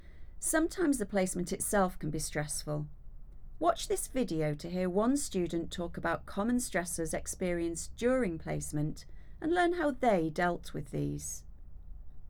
Sometimes the placement itself can be stressful. Watch this video to hear one student talk about common stressors experienced during placement, and learn how they dealt with these.